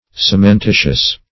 Cementitious \Cem`en*ti"tious\, a. [L. caementitius pertaining